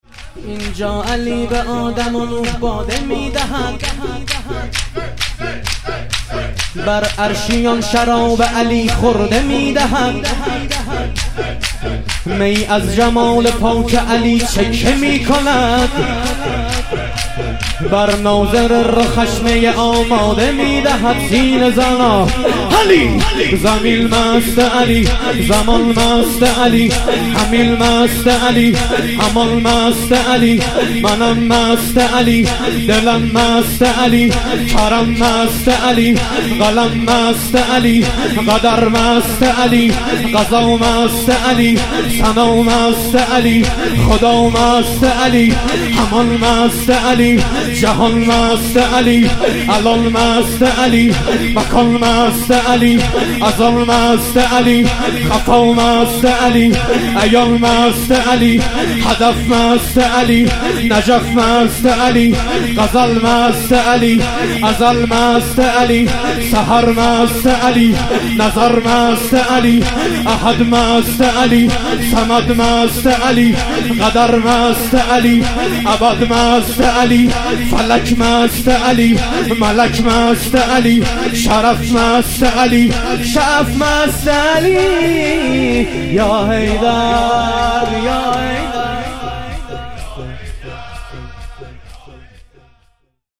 ظهور وجود مقدس امام حسن عسکری علیه السلام - شور